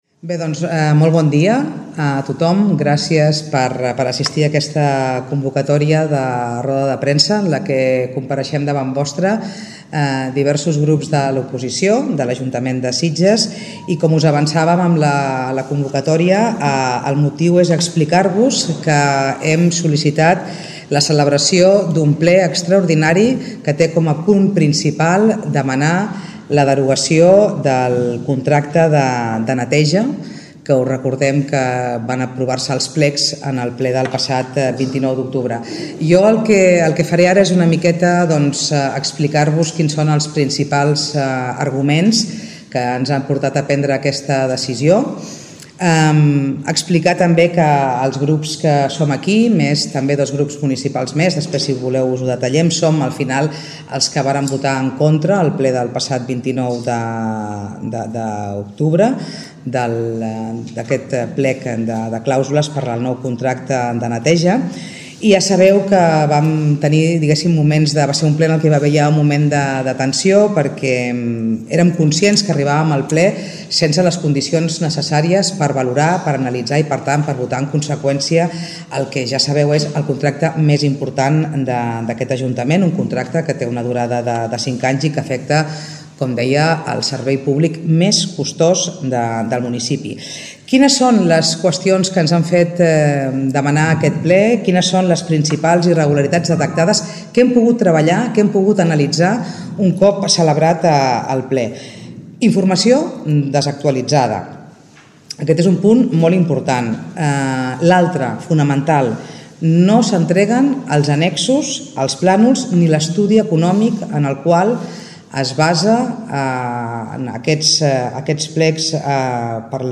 Escolteu la roda de premsa íntegra
Els partits signants han demanat també la dimissió de la regidora d’imatge i serveis urbans Cristina Guiu. Ho expliquen Mònica Gallardo, portaveu de Junts per Sitges, Elena Alonso, portaveu d’El Margalló, Eva Garcia, portaveu del Partit Popular i Rosa Tubau regidora de Junts per Sitges.